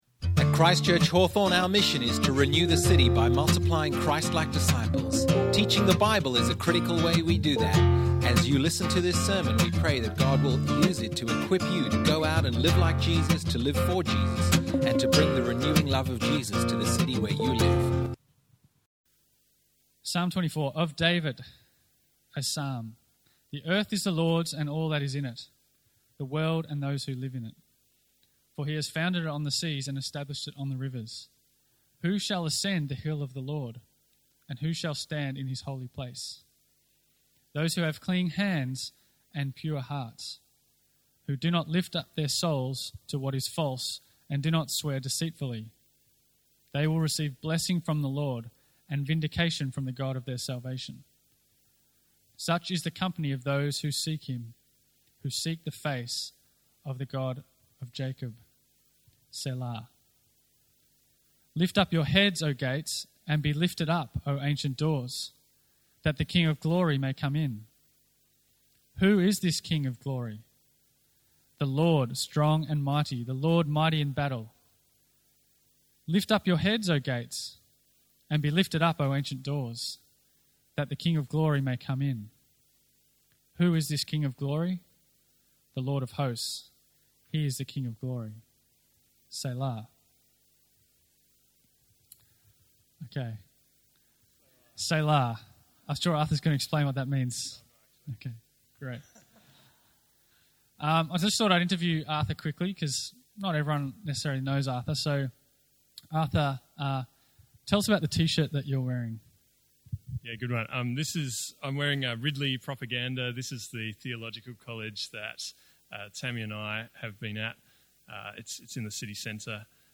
The King of Glory (Psalm 24 sermon)